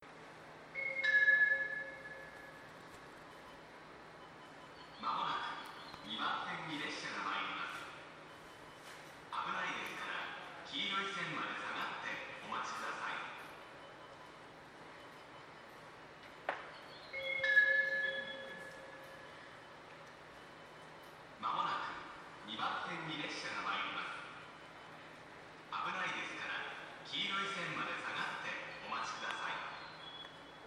この駅では接近放送が設置されています。
接近放送上り電車　接近放送です。